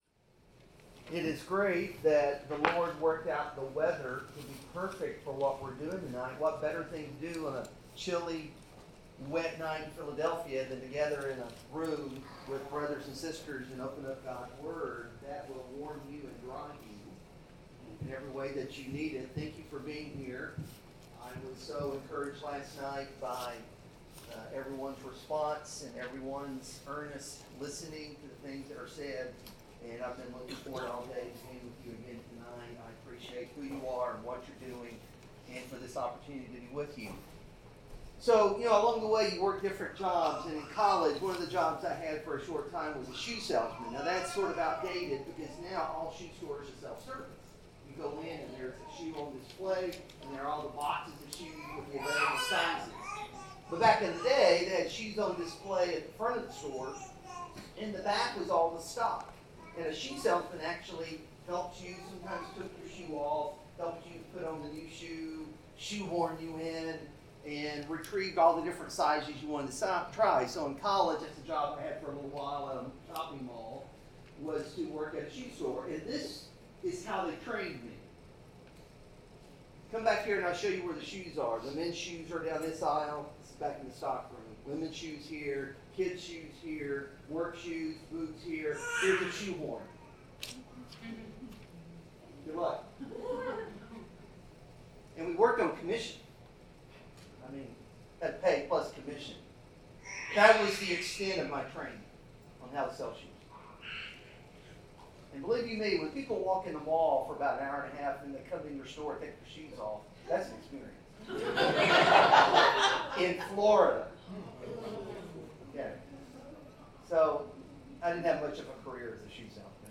Passage: 1 Thessalonians 1-5 Service Type: Sermon